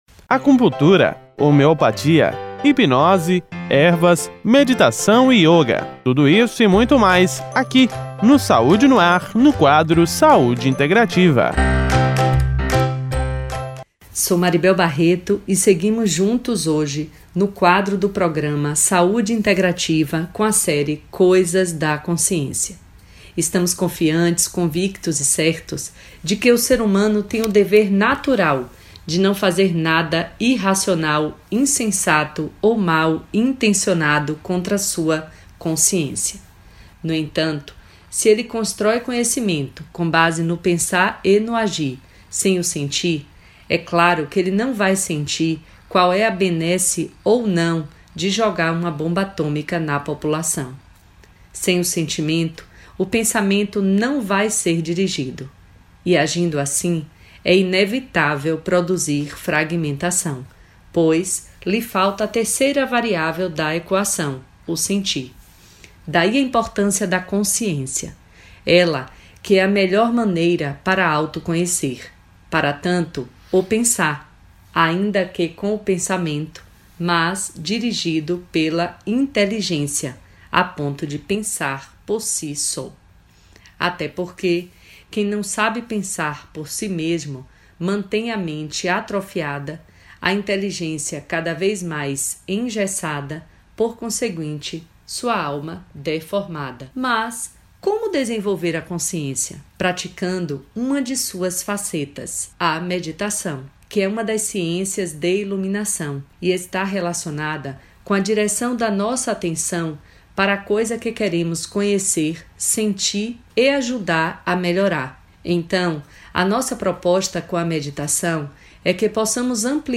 uma participação no Programa Saúde no Ar, da Rádio Excelsior AM 840